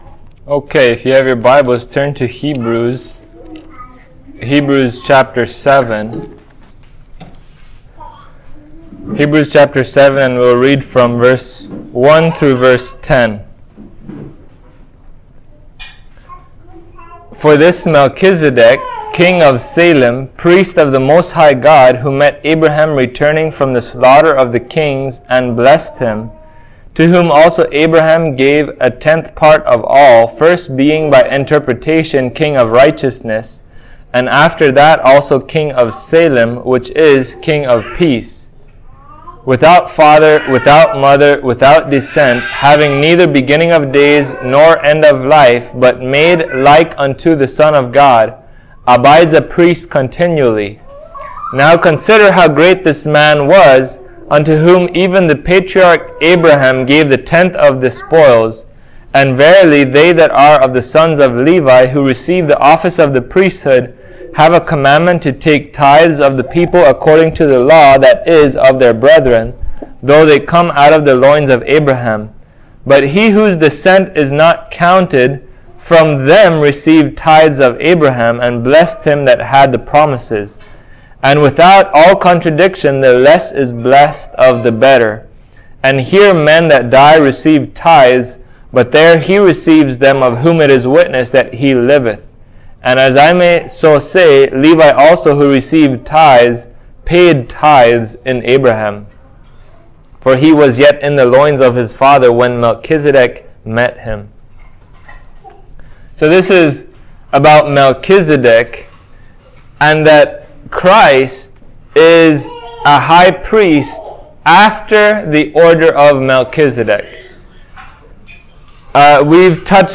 Hebrews 7:1-10 Service Type: Sunday Morning Who is mysterious Melchizedek?